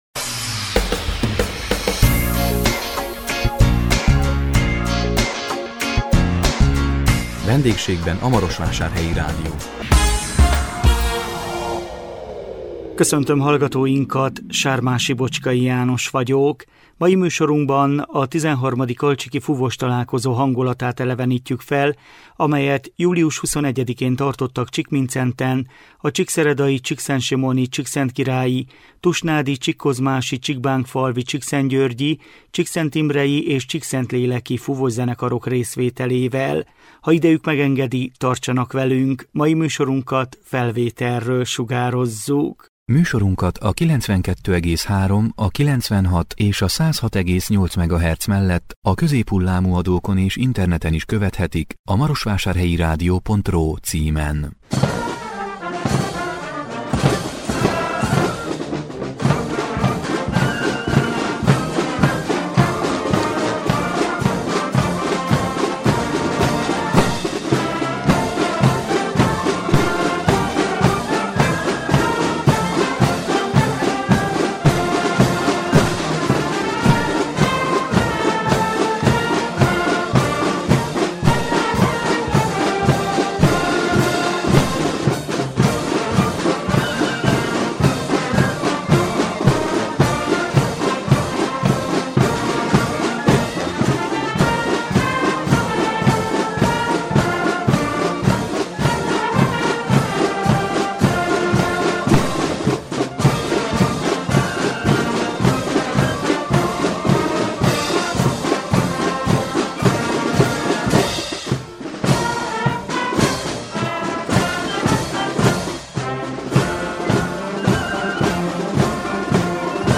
A 2024 augusztus 15-én közvetített VENDÉGSÉGBEN A MAROSVÁSÁRHELYI RÁDIÓ című műsorunkban a XIII. Alcsíki Fúvóstalálkozó hangulatát elevenítettük fel, amelyet július 21-én tartottak Csíkmindszenten a csíkszeredai-, csíkszentsimoni-, csíkszentkirályi-, tusnádi-, csíkkozmási-, csíkbánkfalvi-, csíkszentgyörgyi-, csíkszentimrei- és csíkszentléleki fúvószenekarok részvételével.